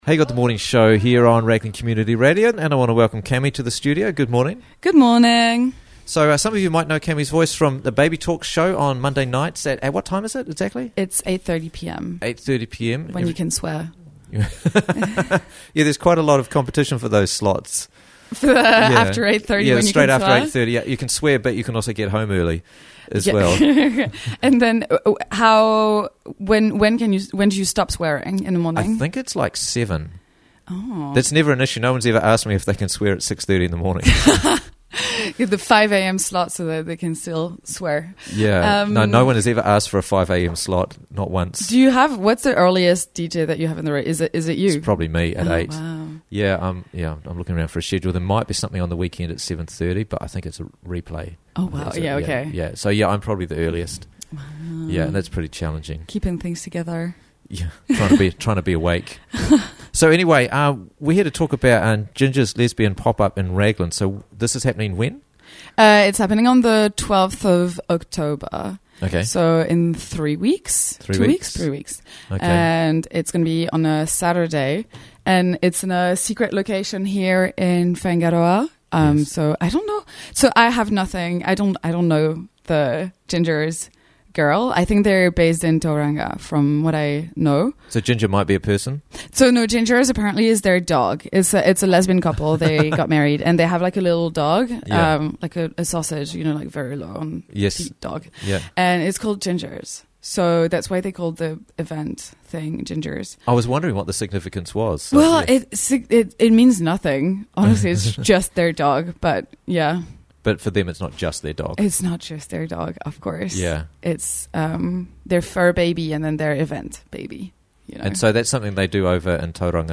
Gingers Lesbian Popup - Interviews from the Raglan Morning Show